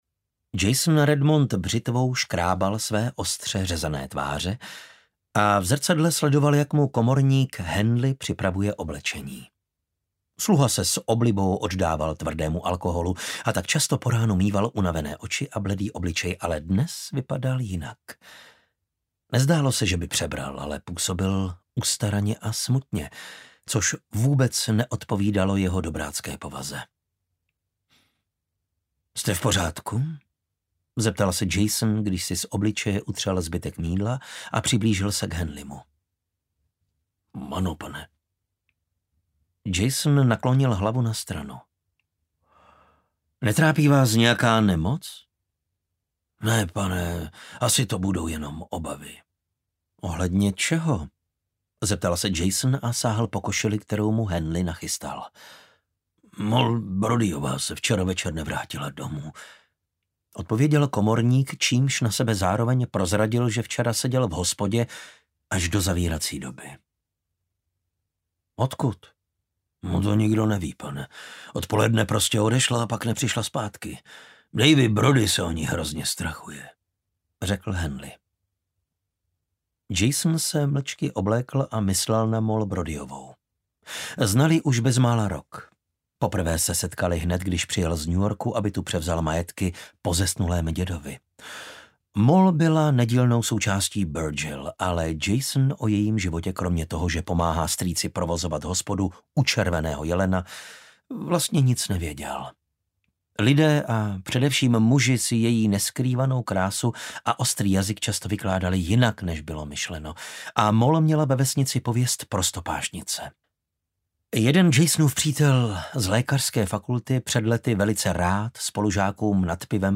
Vražda v maringotce audiokniha
Ukázka z knihy